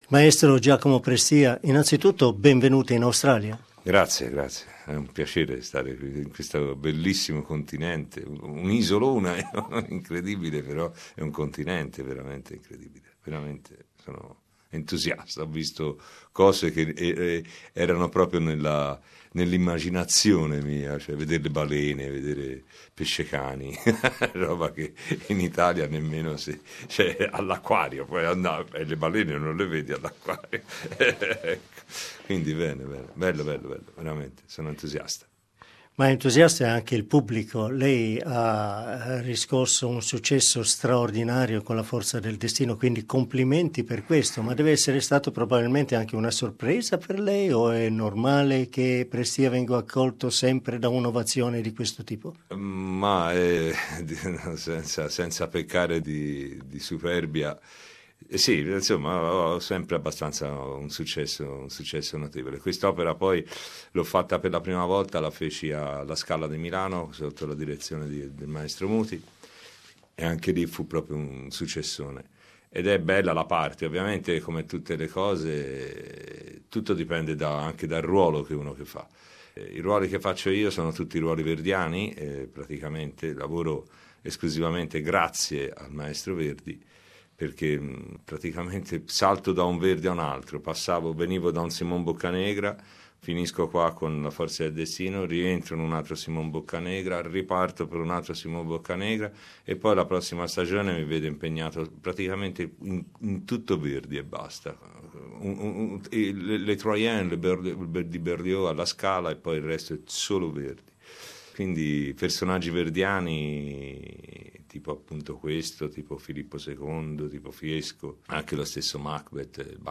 World renowned bass Giacomo Prestia sings for the first time in Australia at the Sydney's Opera House in Verdi's La Forza del Destino. In this lenghty interview he talks about his past, present and future and the experience of seeing a wale off Bondi Beach for the first time in his life.